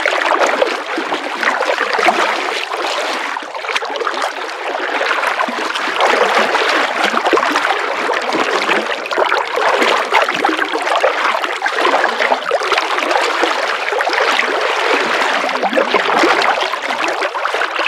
Sfx_creature_discusfish_swim_01.ogg